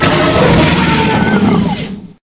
CosmicRageSounds / ogg / general / combat / enemy / droid / bigatt2.ogg